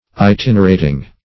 Itinerating - definition of Itinerating - synonyms, pronunciation, spelling from Free Dictionary
& vb. n. Itinerating ([-i]*t[i^]n"[~e]r*[=a]`t[i^]ng).]